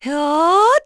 Laudia-Vox_Casting2_kr.wav